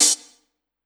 14OHH 01  -L.wav